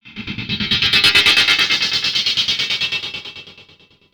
En mode MULTI, double Layers, nouvelles assignations.
(1) L'intro + douce et le décalage du "noise" du début en doublant l'attaque.
(4) La montée du volume, pour atteindre le pic "overdrive" en re-aiguillant une enveloppe.
Le plus proche, à ce jour, du fameux "FX BIONIQUE" (100% au Virus TI):